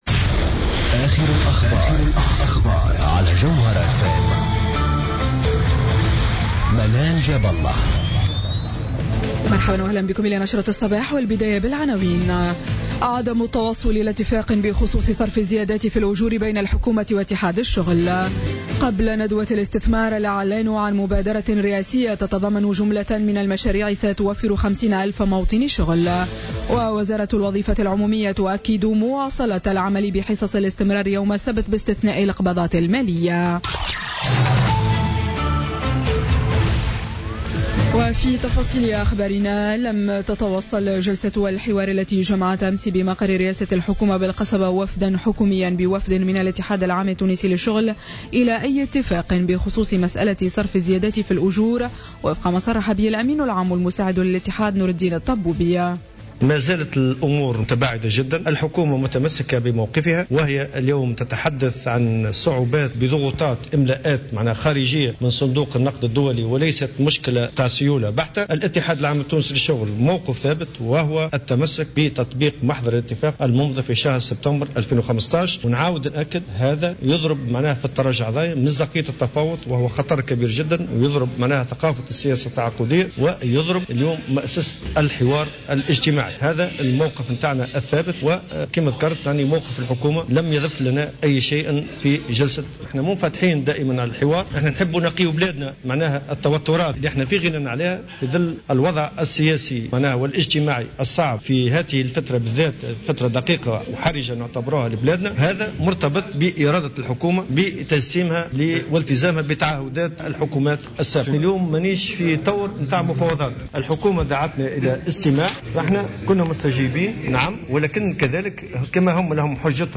نشرة أخبار السابعة صباحا ليوم السبت 26 نوفمبر 2016